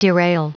Prononciation du mot derail en anglais (fichier audio)
Prononciation du mot : derail